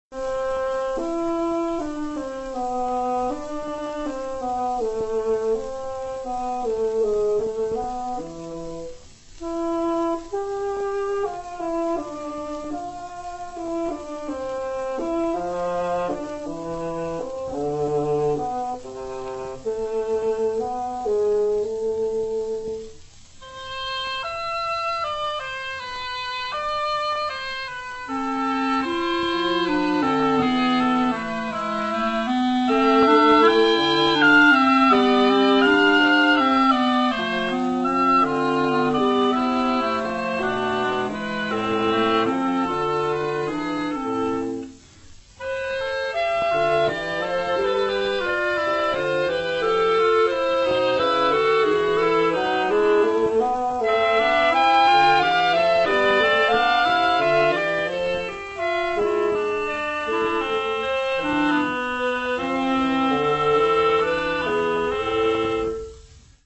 hautbois, clarinette et basson